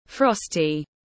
Frosty /ˈfrɒs.ti/